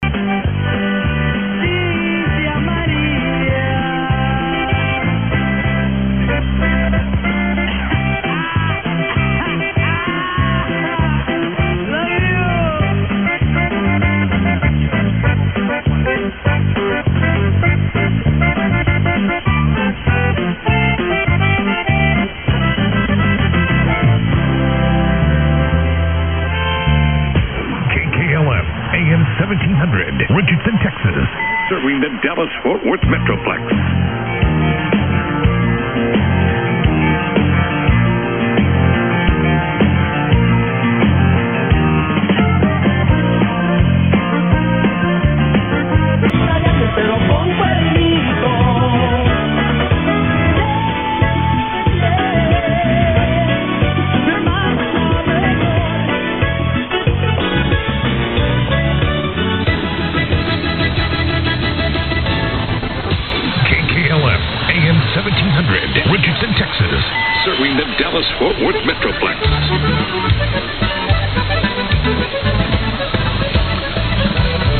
Yesterday morning again, there was a powerhouse signal from KKLF Texas on 1700, with it's new SS music format. Booming at 0300 and at 0600.